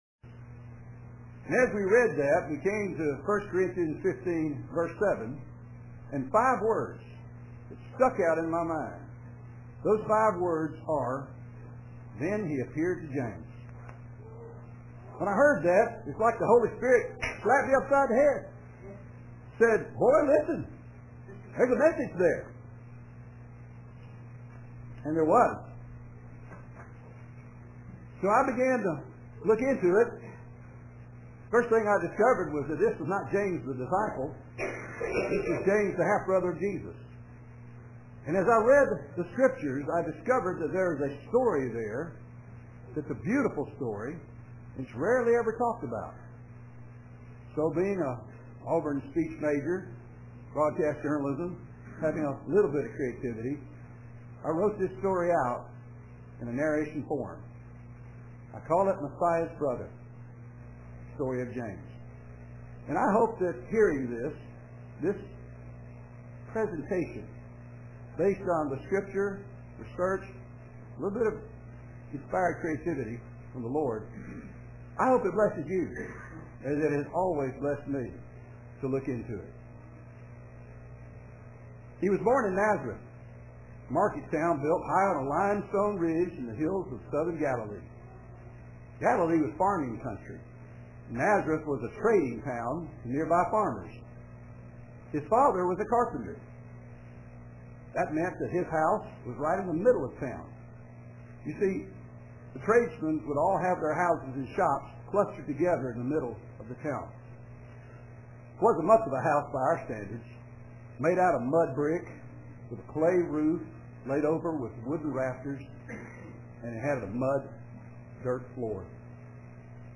Story Sermon